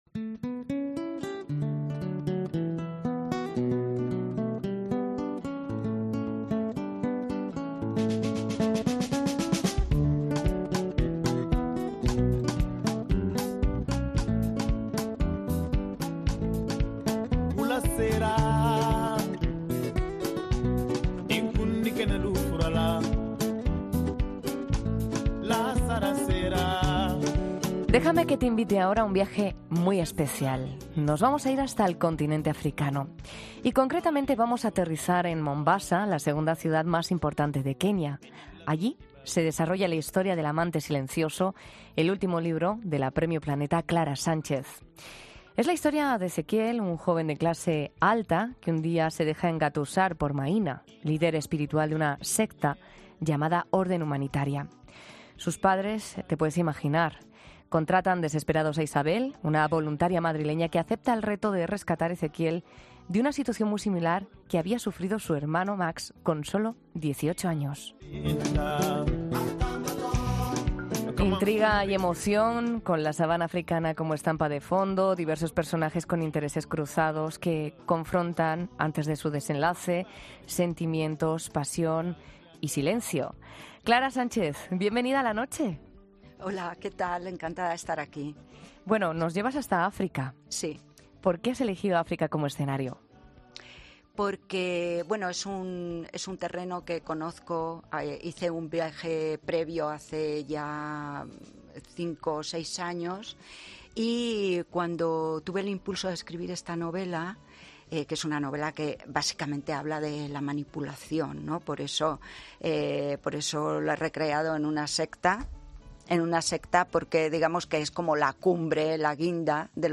La escritora presenta en 'La Noche' de COPE su libro 'El amante silencioso'